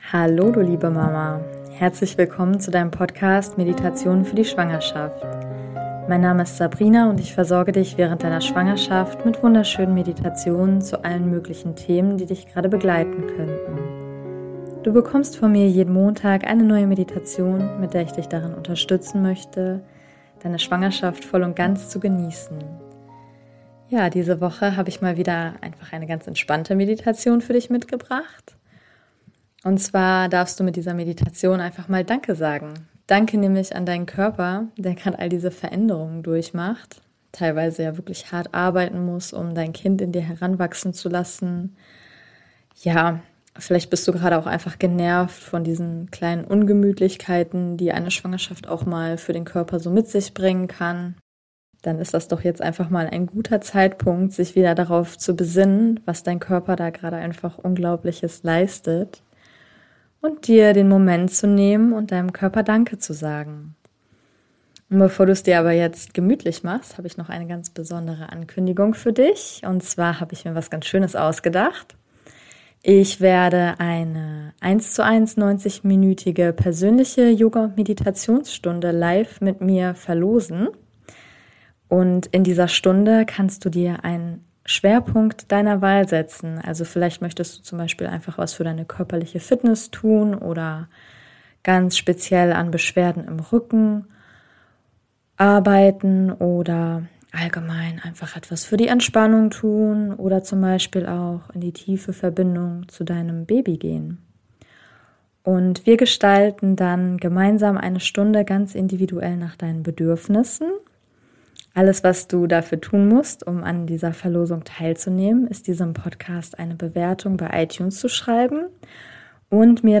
Diese Woche gibt es eine ganz entspannte Meditation für dich. Und zwar darfst du in dieser Meditation einfach mal Danke sagen.